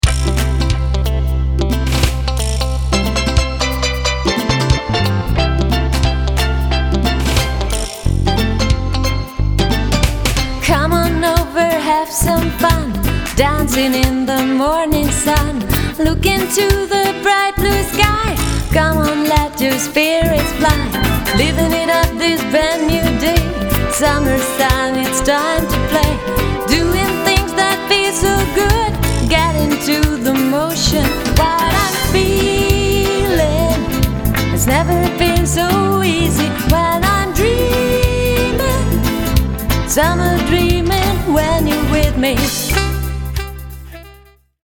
Sängerin, Sänger/Keyboard, Saxophon/Bass, Gitarre, Drums